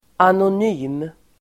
Uttal: [anon'y:m]